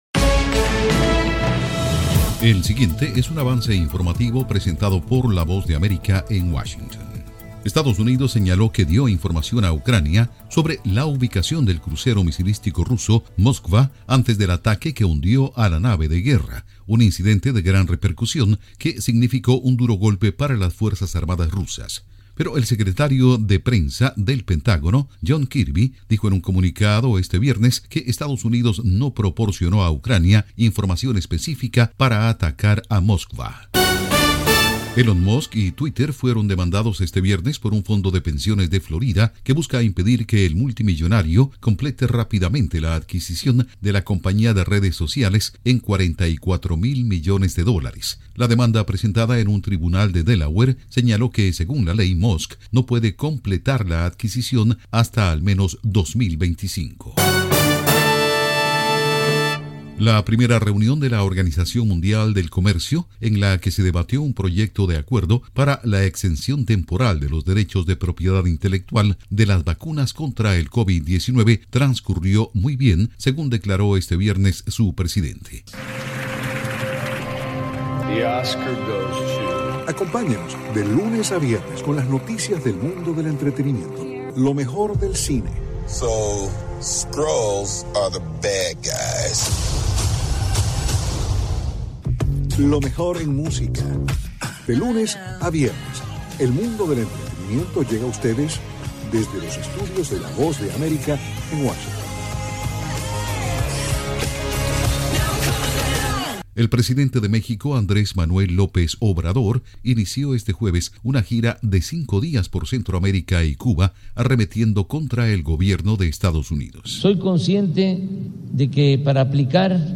El siguiente es un avance informativo presentado por la Voz de América en Washington.